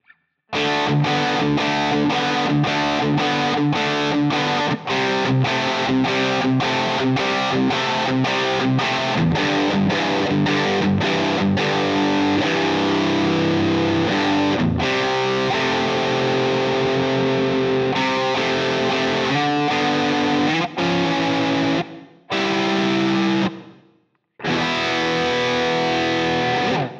После отстройки всё было очень даже ничего до момента, пока не попробовал сыграть обычные интервалы на 3-4-5-6 струнах (на скриншоте видно, про какие интервалы идёт речь). Спасает "нестрой" только принудительный бенд одной из струн.
Сразу уточню: струны не пережимаю, и нестрой на интервалах не зависит от атаки медиатором; его можно "словить" до 6-го и после 12-лада.